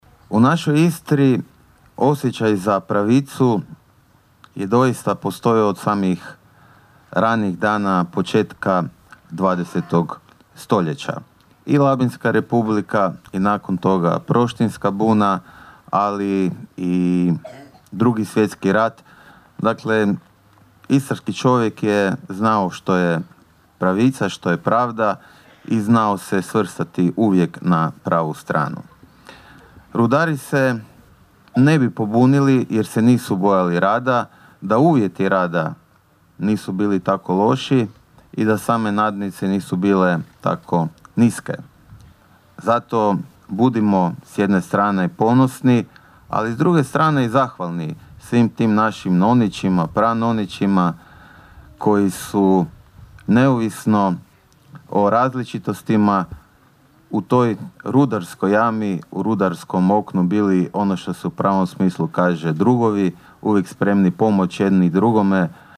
Komemoracija na Krvovoj placi na Vinežu
O zajedništvu i pravednosti govorio je i istarski župan Boris Miletić: (